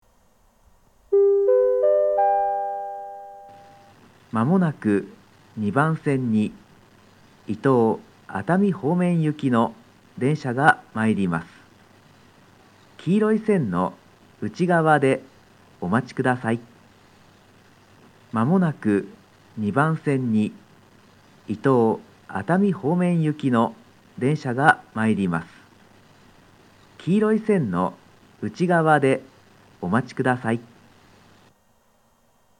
（男性）
上り接近放送